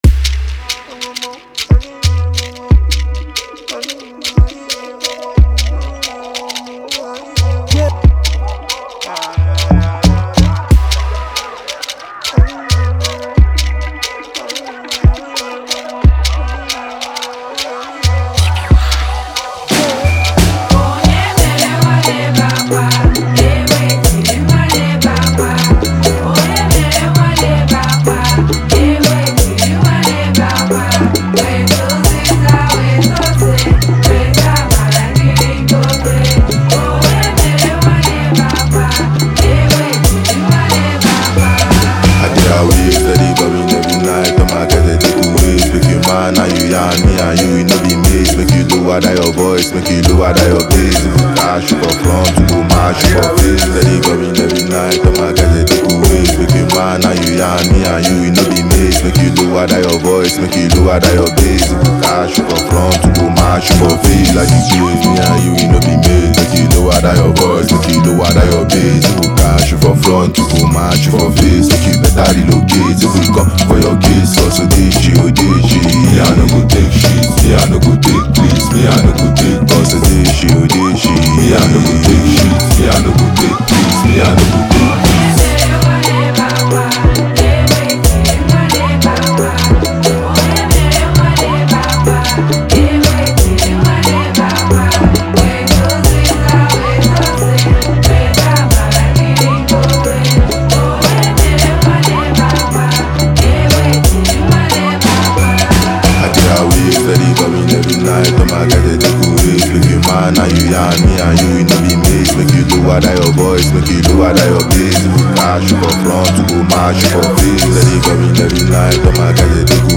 a captivating chorus from the talented female vocalist
By blending ancient echoes with vibrant modern energy